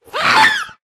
scream3.ogg